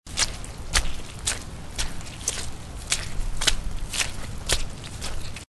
На этой странице собраны натуральные звуки шагов по лужам: от легкого шлепанья до энергичного хлюпанья.
Звук шагов по влажным тропам: Шаги в сырой глине